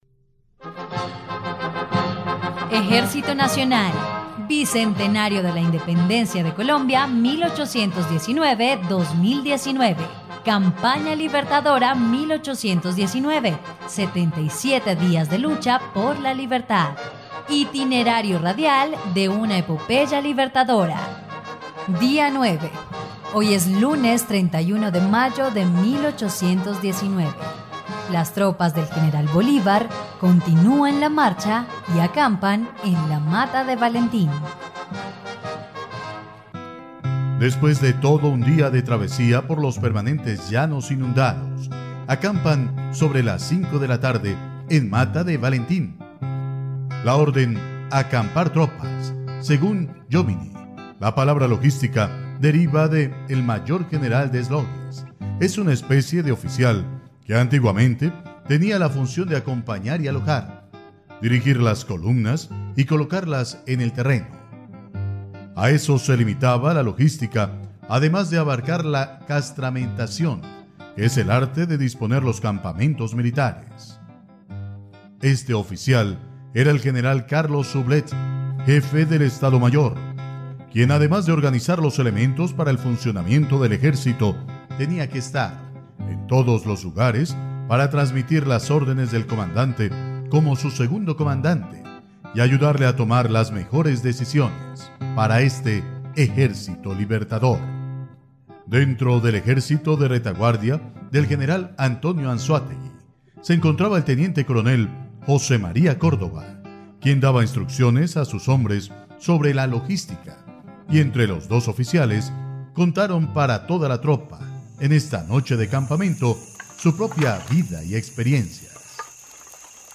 dia_09_radionovela_campana_libertadora.mp3